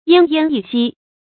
淹淹一息 yān yān yī xī
淹淹一息发音